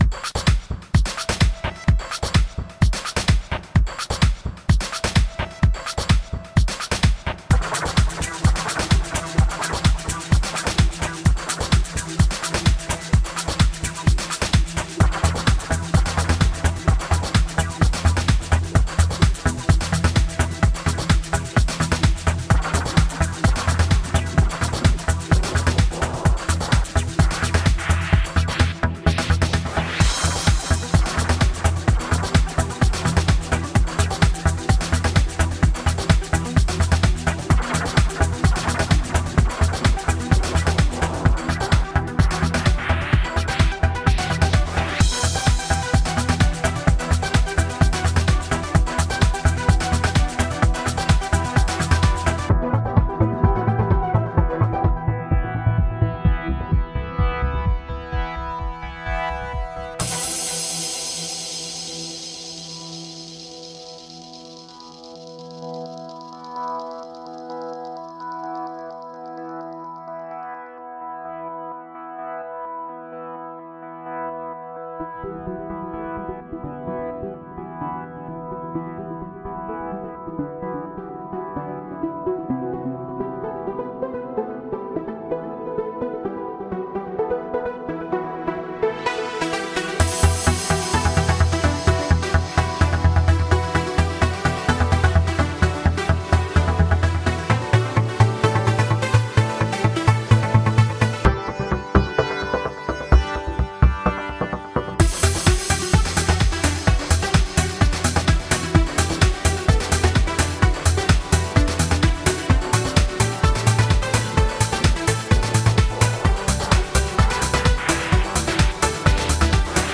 BPM128
MP3 QualityMusic Cut